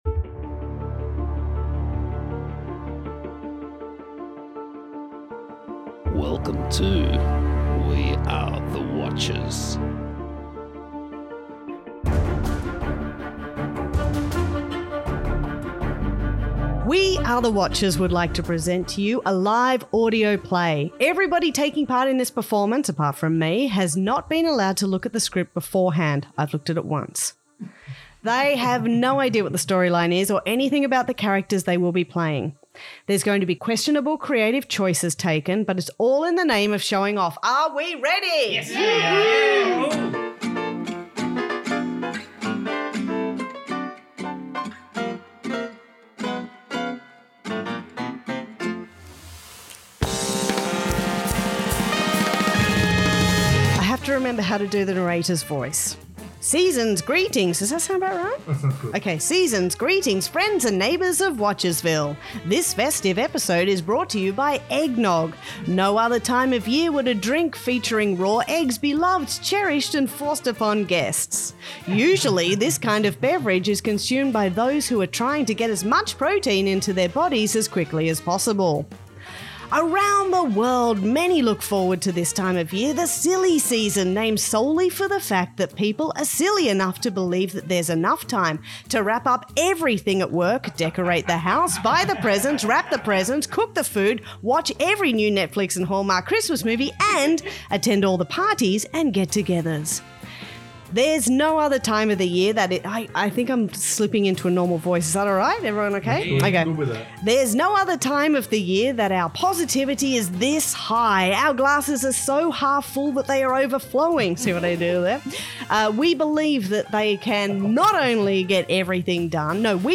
Everyone taking part in this performance has not been allowed to look at the script beforehand.